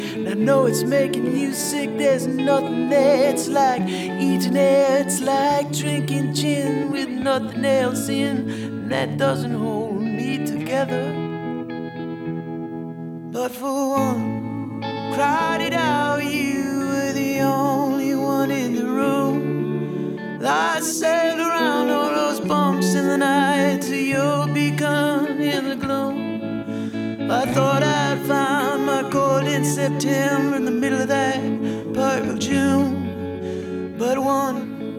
Жанр: Иностранный рок / Рок / Инди / Альтернатива / Фолк-рок